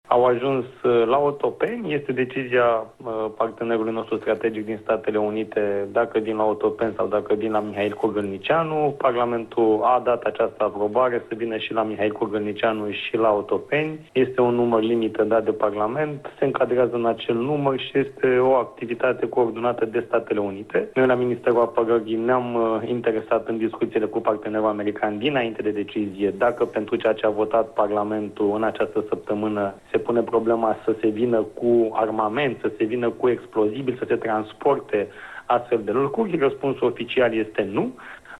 Primele trei avioane-cisternă ale Statelor Unite au ajuns în România, în contextul operațiunilor militare din Orientul Mijlociu. Informația a fost confirmată oficial aseară de ministrul Apărării, Radu Miruță, pentru postul public de televiziune.
16mar-07-Miruta-despre-avioanele-americane-care-au-ajuns-in-Romania.mp3